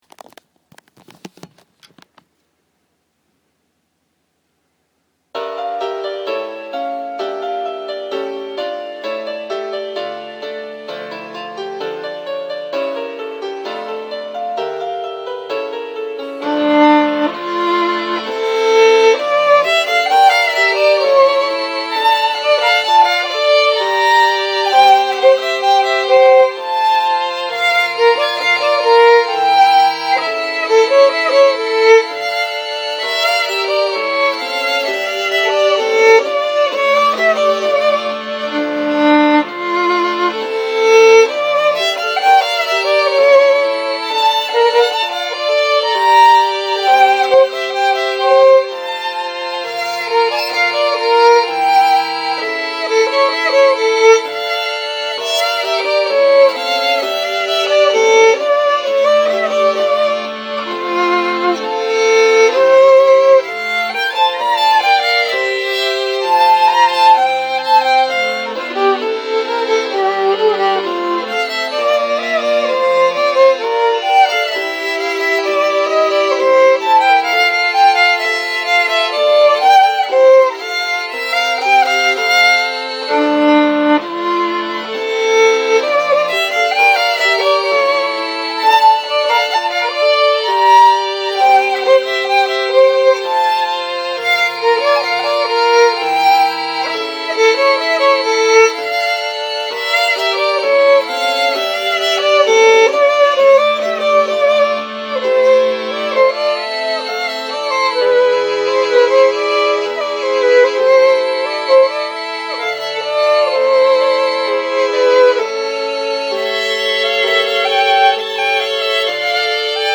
ちなみに去年のﾚｽﾄﾗﾝ余興前に録音したのもひょっこり出てきたので、ついでにｱｯﾌﾟ。
Vn2、Vc、Pf…自作PC音源